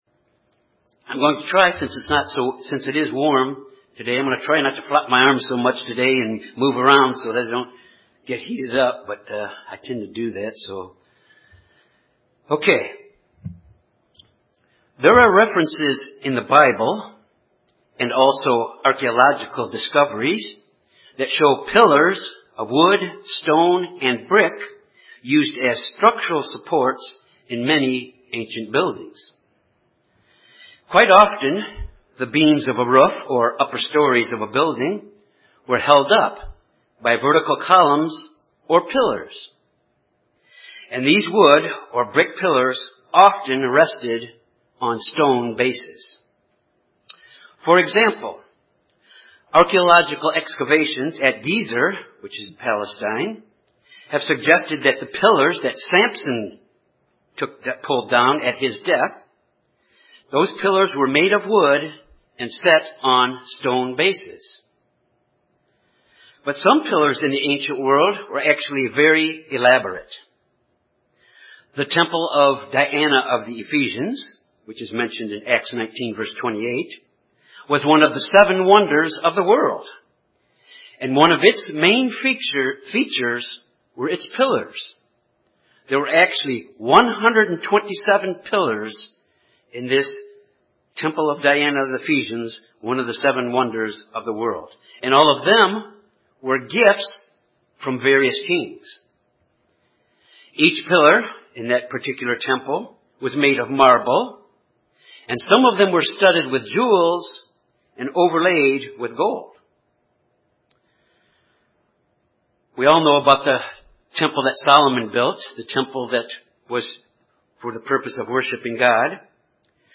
This sermon examines three ways we can be effective pillars in the church.